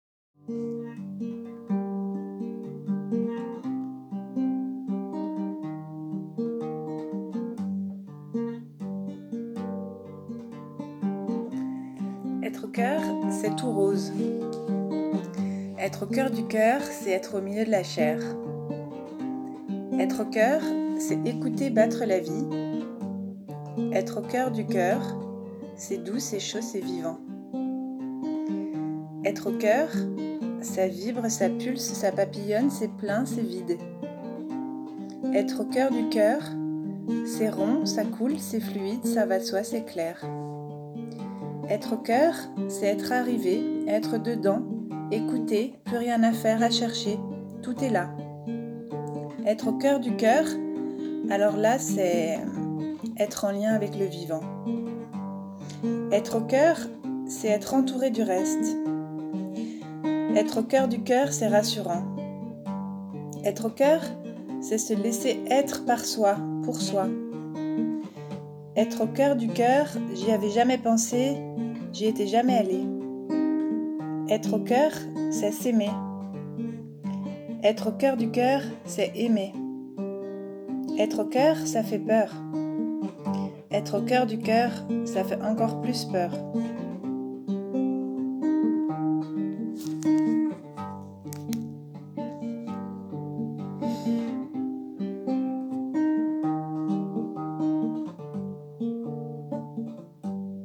nous publions « Au cœur du cœur », un poème
sur une musique